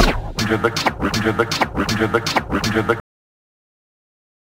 Percussive Dj Scratch